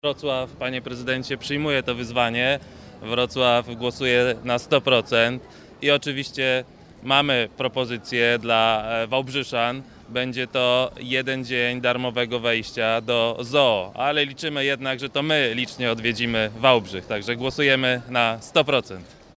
– Przyjmujemy wyzwanie! – ogłosił Michał Młyńczak, wiceprezydent Wrocławia. Jeśli to Wałbrzych osiągnie lepszy wynik, wrocławskie ZOO udostępni jeden dzień darmowego wstępu dla mieszkańców Wałbrzycha.